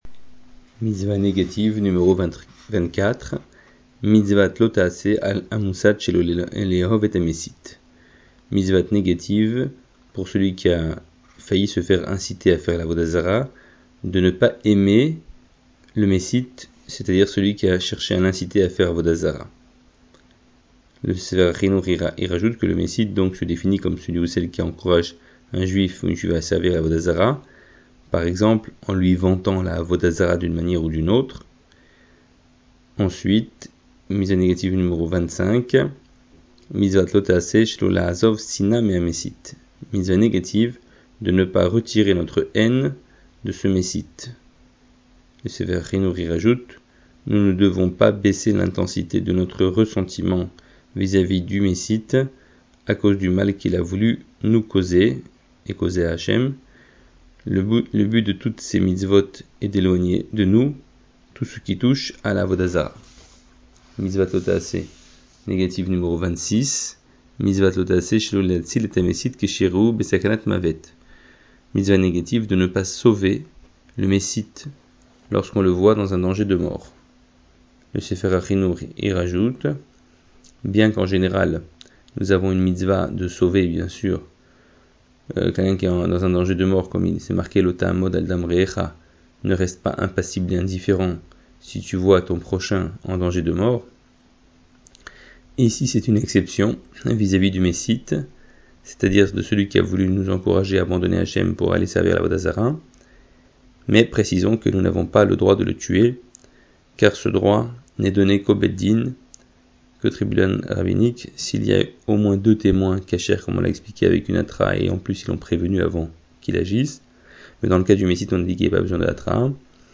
Résumé du cours audio: Définition : Méssite = personne qui incite à faire Avoda Zara (dans ce contexte).